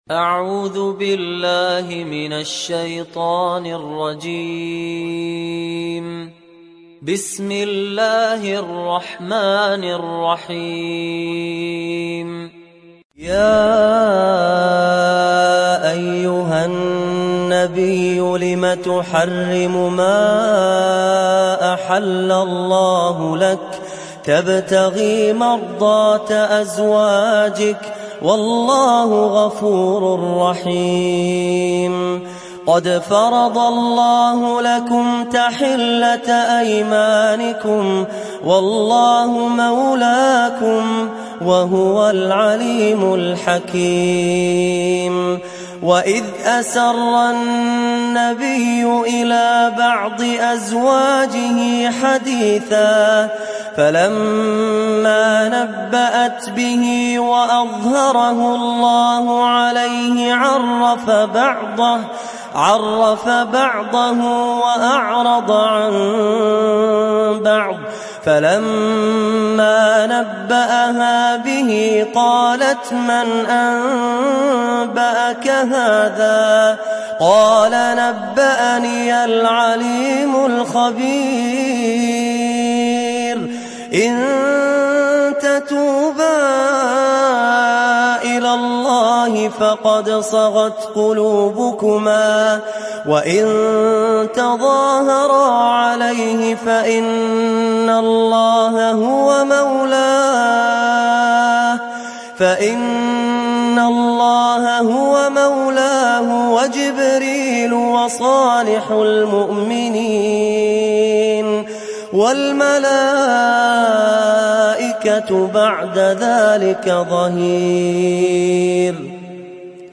تحميل سورة التحريم mp3 بصوت فهد الكندري برواية حفص عن عاصم, تحميل استماع القرآن الكريم على الجوال mp3 كاملا بروابط مباشرة وسريعة